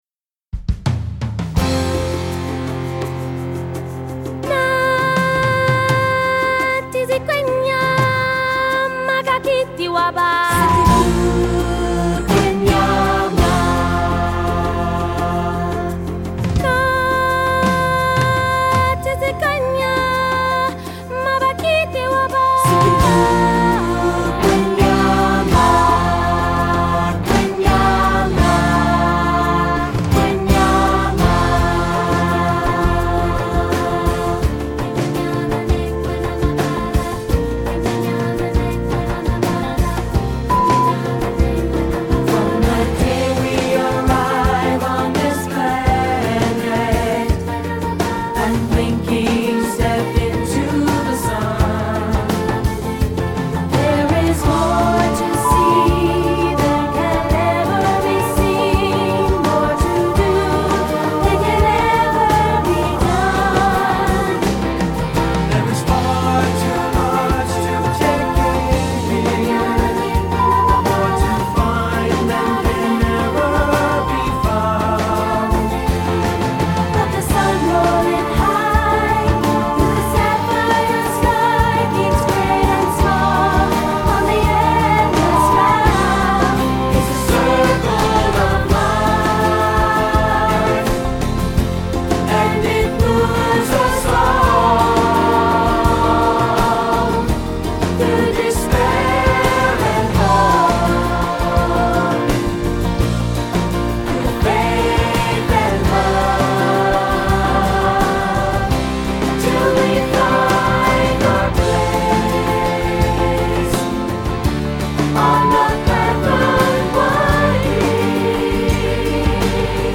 features the powerful rhythms and warm harmonies